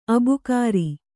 ♪ abukāri